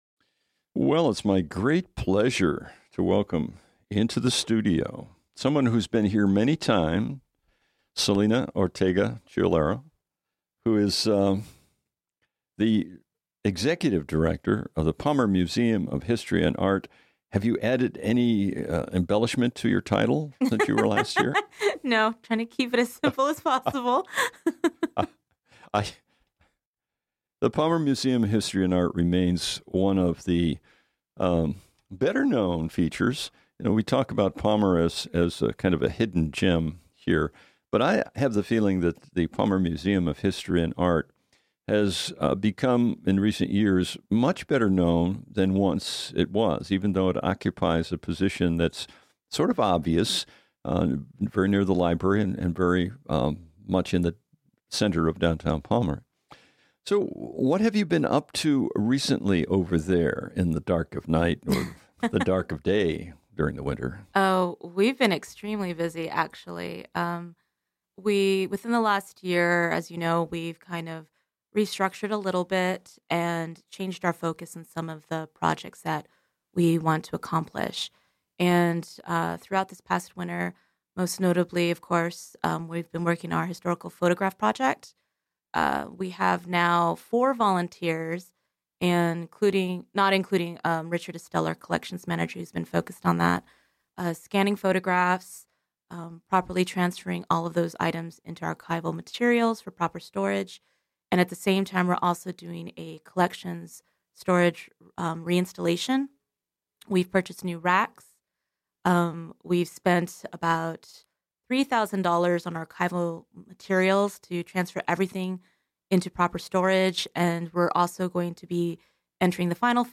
Discussions and interviews with employees and administrators from the city of Palmer